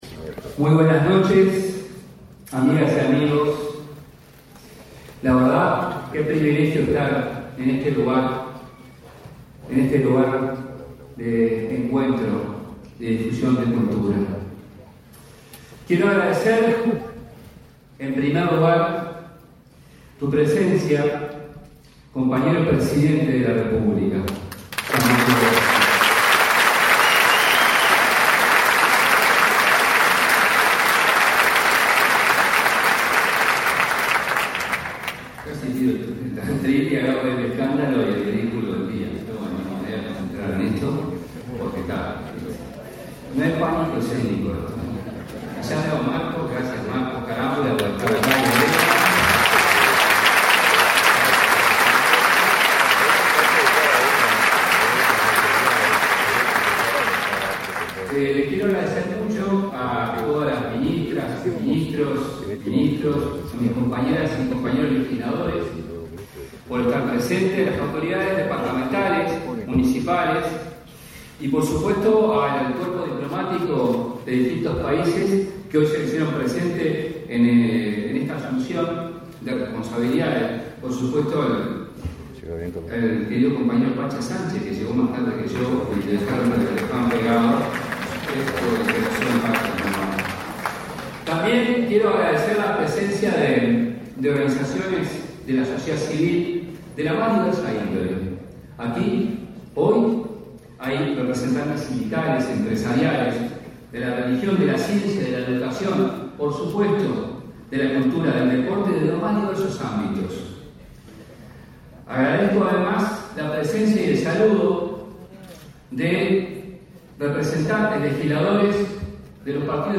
Palabras del ministro de Educación y Cultura, José Carlos Mahía
Palabras del ministro de Educación y Cultura, José Carlos Mahía 05/03/2025 Compartir Facebook X Copiar enlace WhatsApp LinkedIn El presidente de la República, Yamandú Orsi, y la vicepresidenta, Carolina Cosse, participaron, este 5 de marzo, en la ceremonia de asunción de las autoridades del Ministerio de Educación y Cultura. Asumió el ministro, José Carlos Mahía, y la subsecretaria, Gabriela Verde.